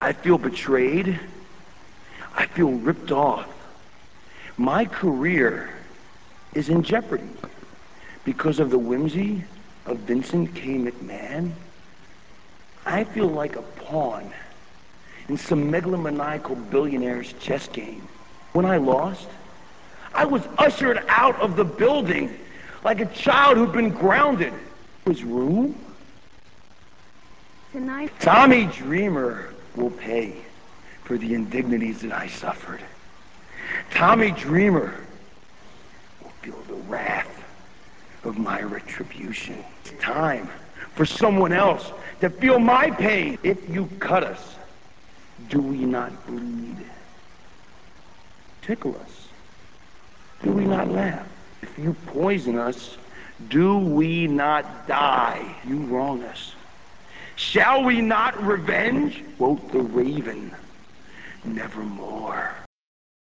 raven72.rm - This clip comes from WWE Heat - [07.07.02]. Raven talks to Terri about how he'll exact his revenge on Tommy Dreamer for his role in Raven's expulsion from RAW.